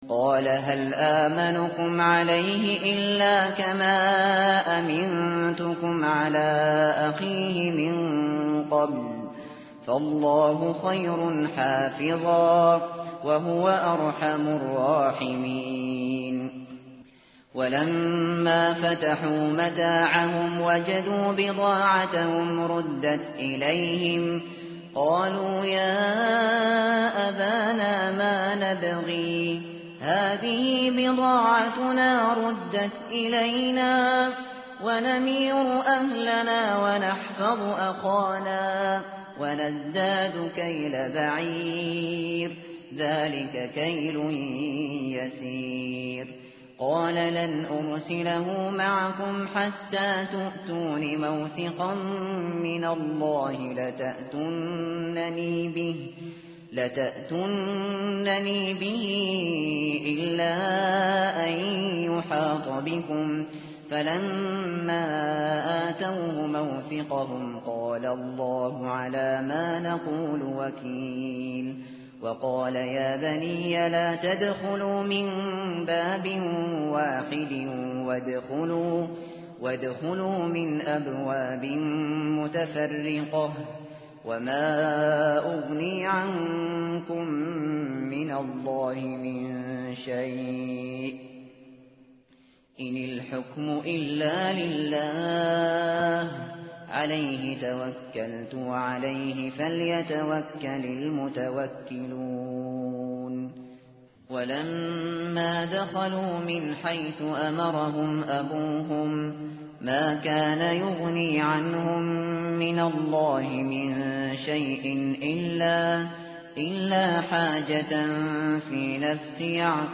متن قرآن همراه باتلاوت قرآن و ترجمه
tartil_shateri_page_243.mp3